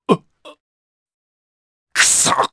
Baudouin-Vox_Dead_jp.wav